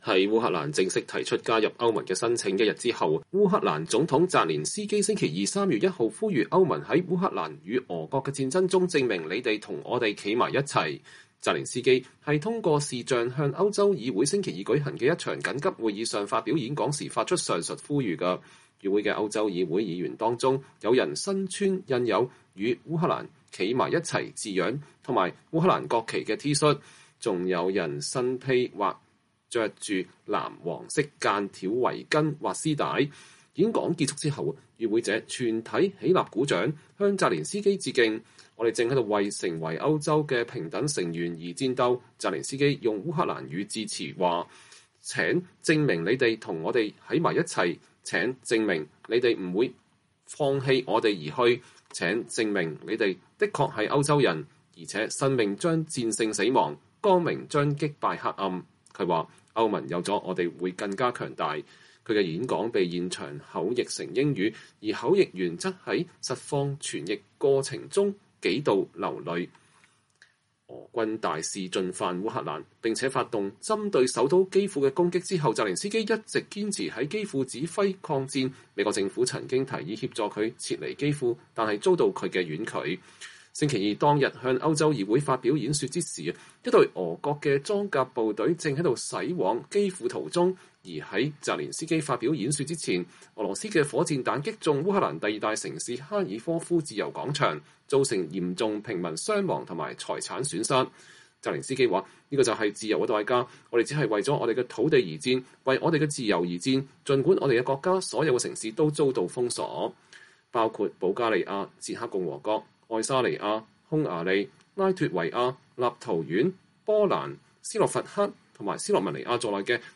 澤連斯基是通過視頻向歐洲議會星期二舉行的一場緊急會議上發表演講時發出上述呼籲的。
演講結束後，與會者全體起立鼓掌，向澤倫斯基致敬。
他的演說被現場口譯成英語，而口譯員則在實況傳譯過程中數度流淚哽咽。